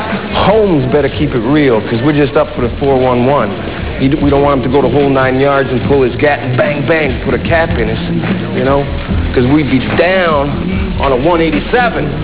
Talkin' like a gangsta